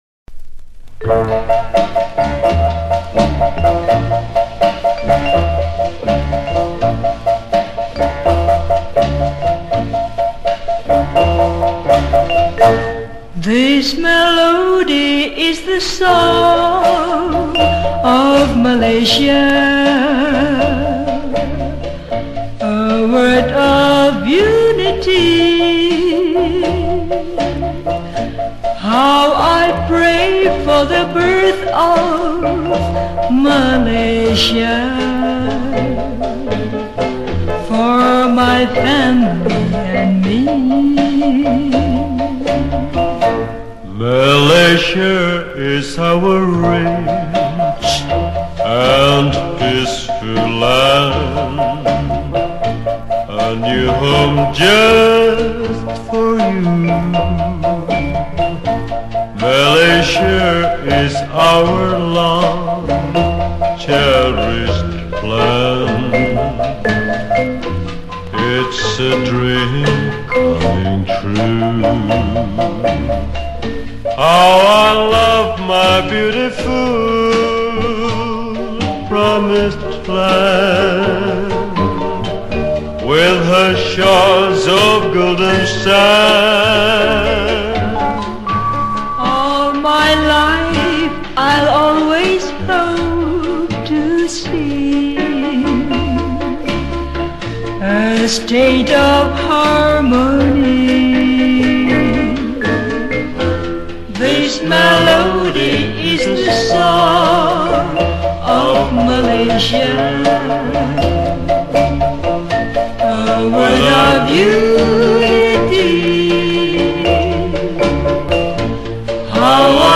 Malaysian Patriotic Retro Song